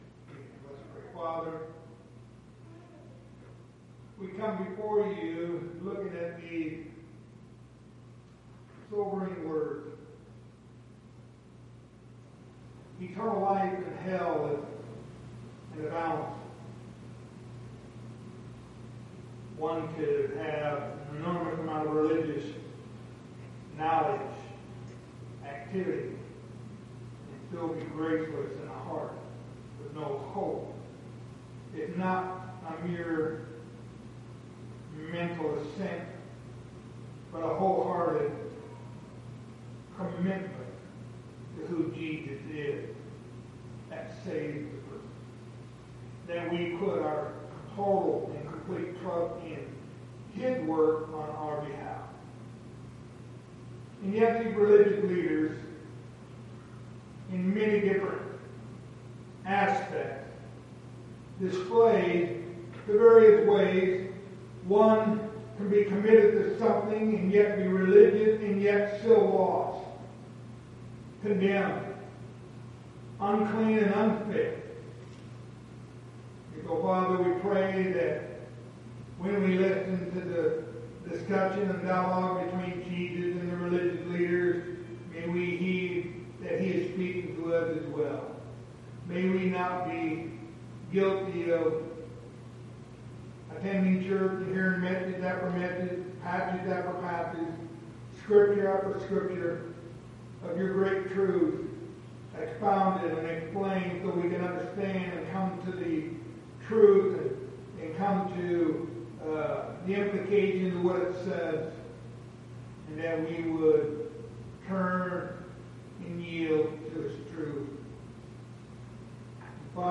Passage: John 8:21-30 Service Type: Wednesday Evening Topics